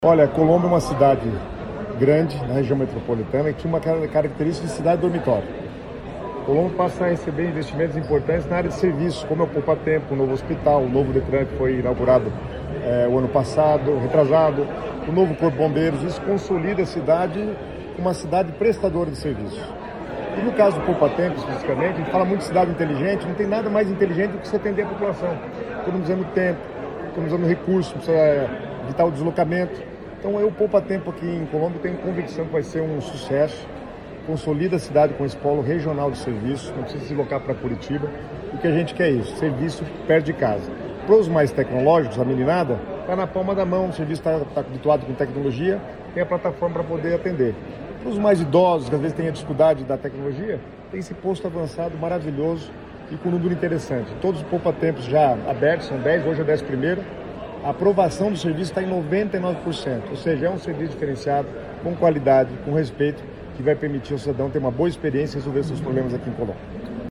Sonora do secretário das Cidades, Guto Silva, sobre a nova unidade do Poupatempo Paraná em Colombo